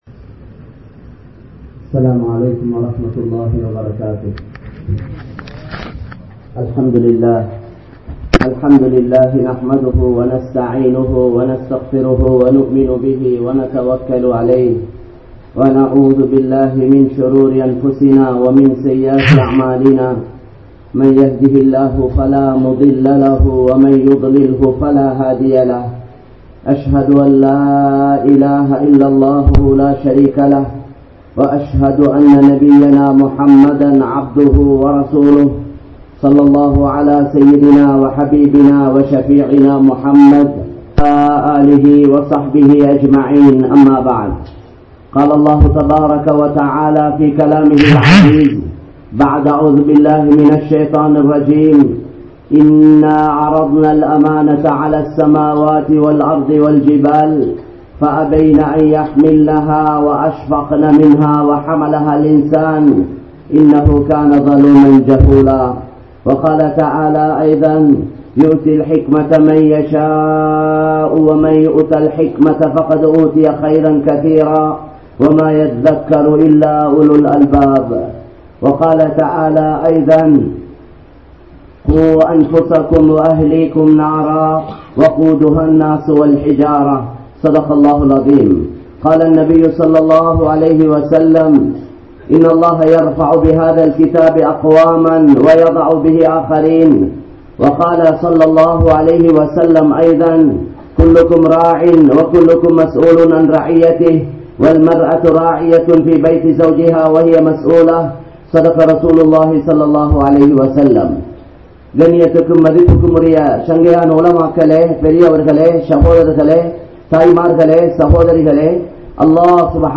Nabi Maarhalin Vaarisuhal (நபிமார்களின் வாரிசுகள்) | Audio Bayans | All Ceylon Muslim Youth Community | Addalaichenai